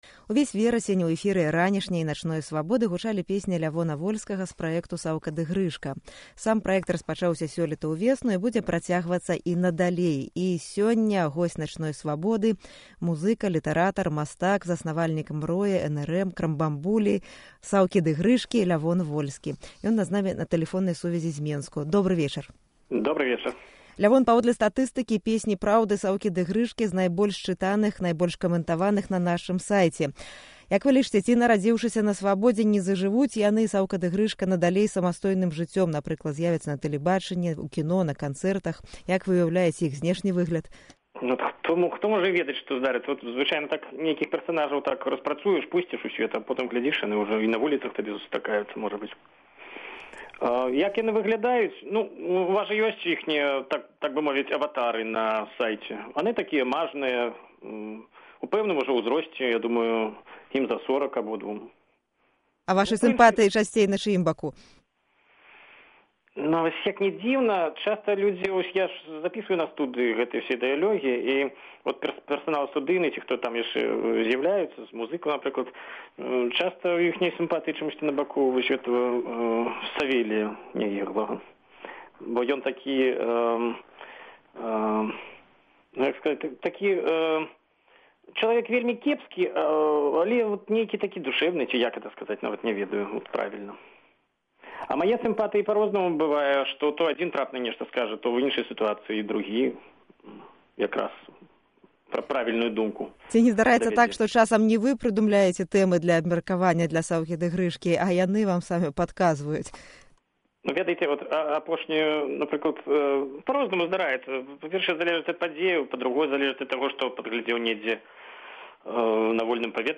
Гутарка зь Лявонам Вольскім